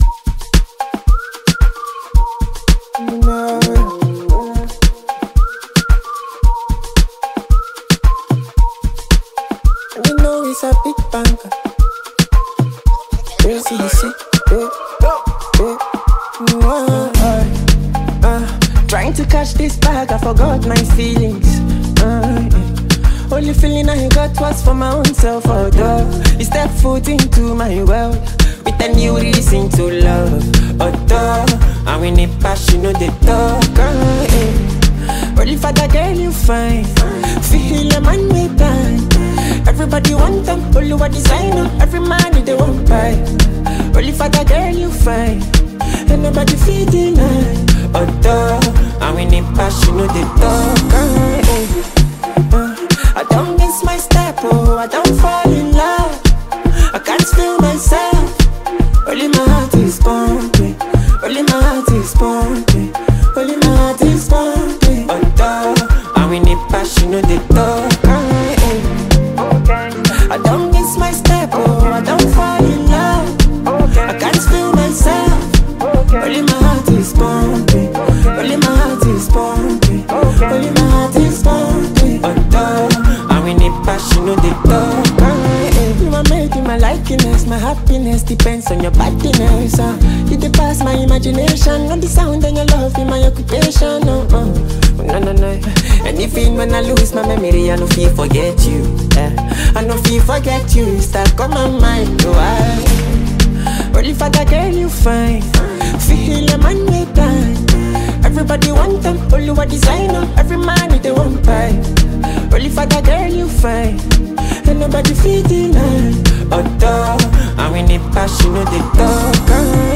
a perfect blend of Afro-fusion and contemporary street vibes
flows effortlessly over the pulsating instrumental